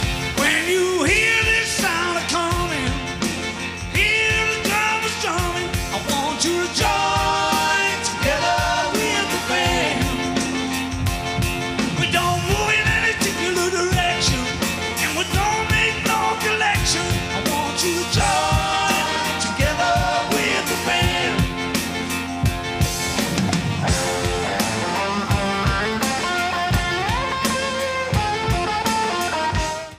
Pre-FM Radio Station Reels